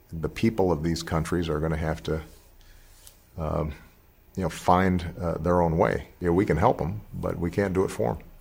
Finally, listen to ex-president Barack Obama saying can and can’t in the same sentence:
Even though it’s challenging, you can hear the differences in the vowels and  the stress. Also notice the pronunciation of them /əm/. The dropping of the /ð/ in this word is a very common feature of informal American English.